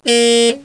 carbeep.mp3